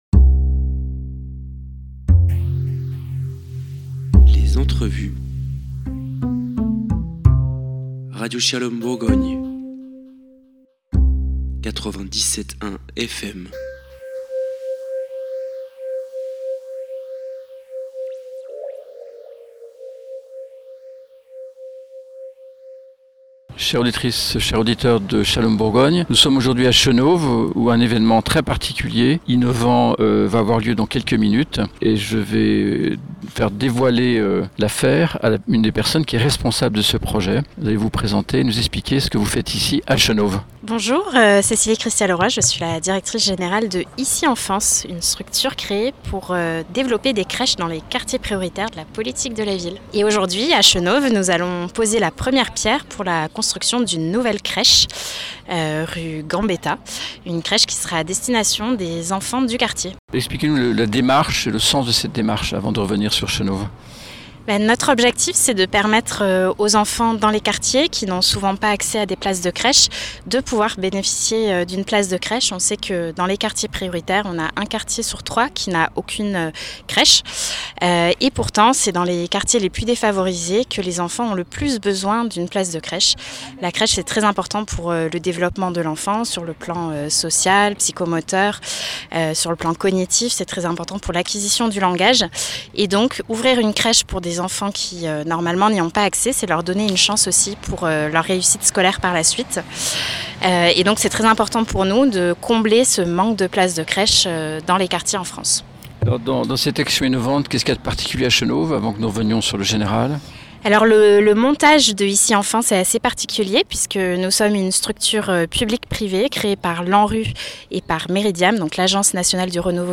Une nouvelle crèche à Chenôve : La pose de la première pierre.